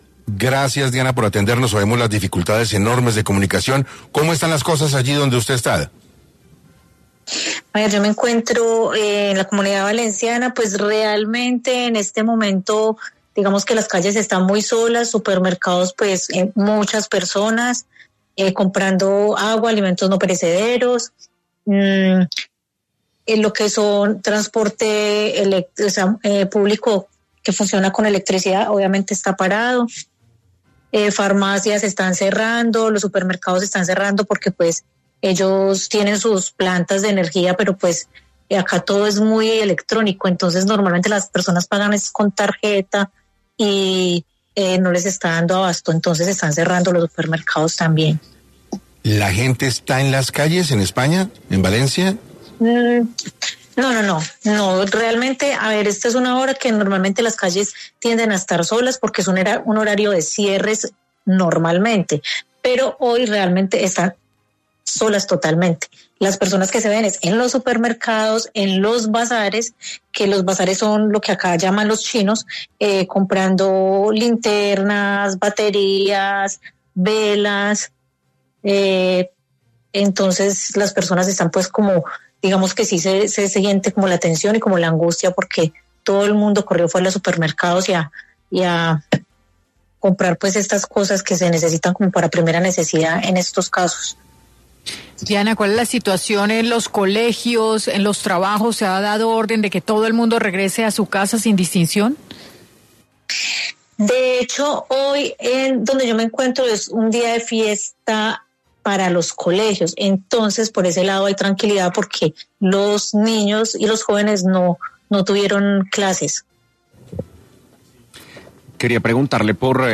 Con ello, la entrevistada expresó que hay un malestar general, pues, la gente está buscando abastecerse lo más pronto posible de linternas, baterías, velas y otros productos de primera necesidad.